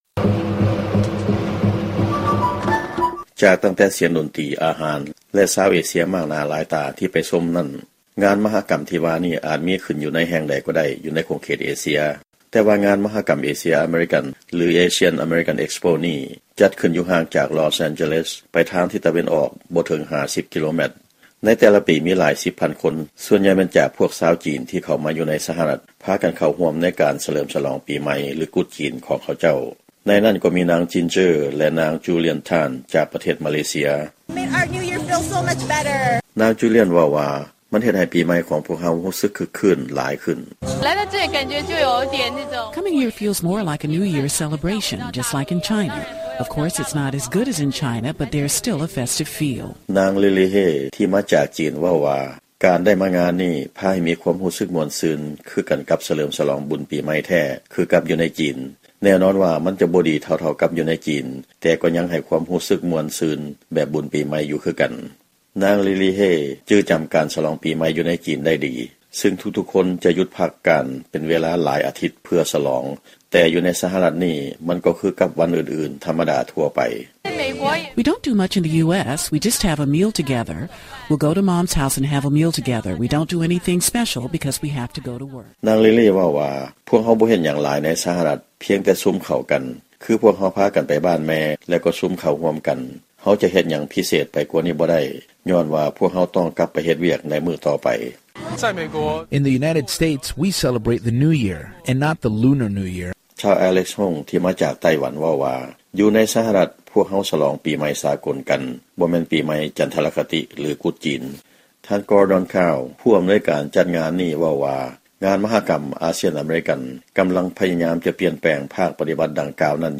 ຟັງລາຍງານ ການສະຫຼອງປີໃໝ່ ຈັນທະລະຄະຕິ ຫຼື ກຸດຈີນ